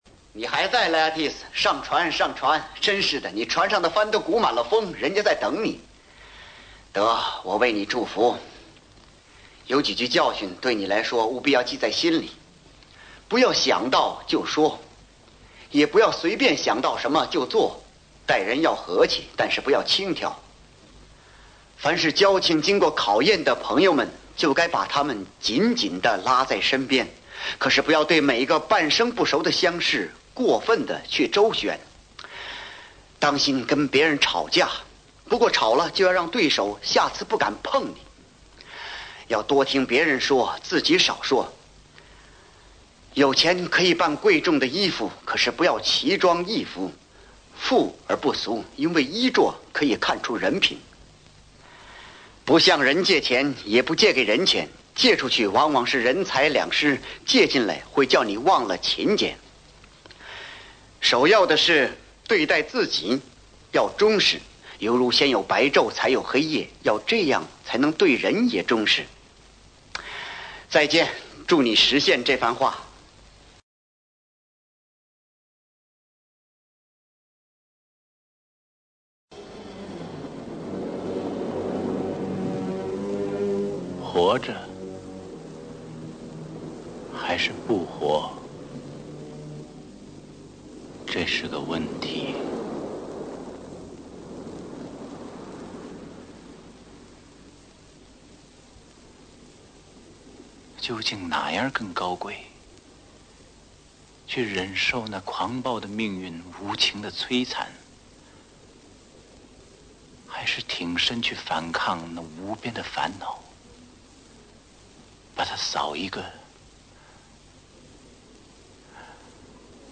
经典电影《王子复仇记》片段 —— 配音：孙道临、邱岳峰等【附经典台词】 激动社区，陪你一起慢慢变老！
请听 劳伦斯·奥立佛版《王子复仇记》（上译厂译配） 配音演员：孙道临、邱岳峰、尚华等